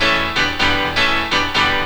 PIANO LOO0AR.wav